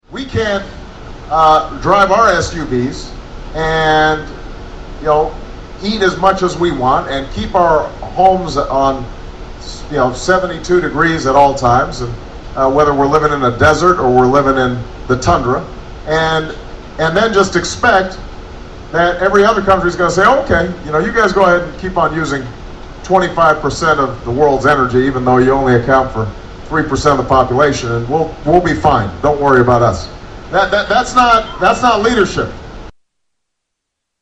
Audio for Barack Obama from May 2008: